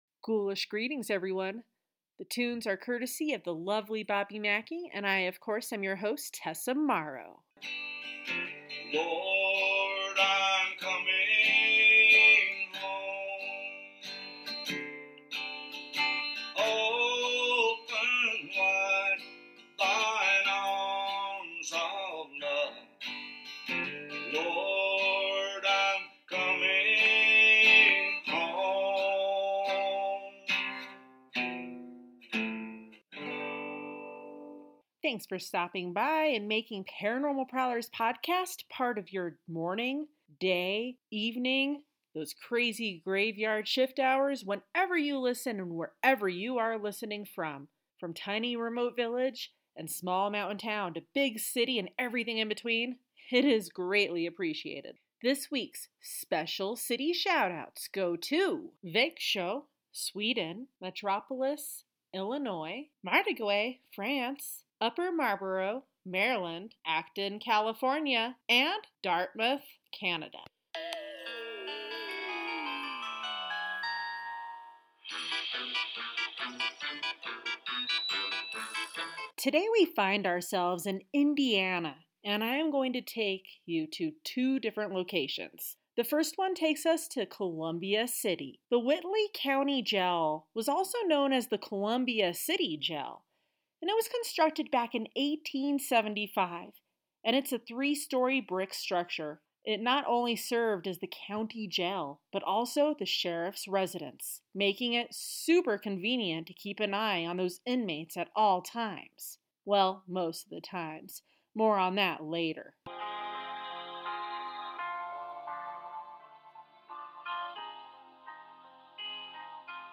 My own recordings: Footsteps